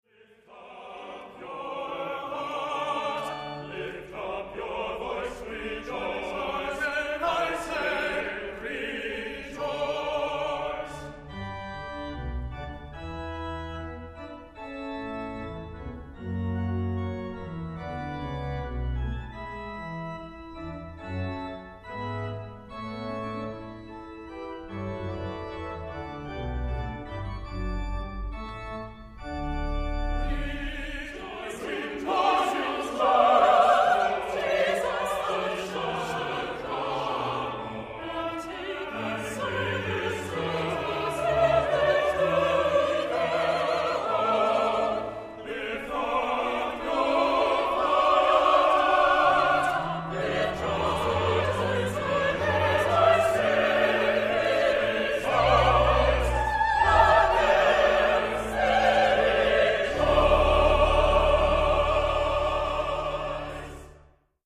buoyant and joyful setting
Children's choir part is optional